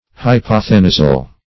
Hypothenusal \Hy*poth`e*nu"sal\, a. Of or pertaining to hypothenuse.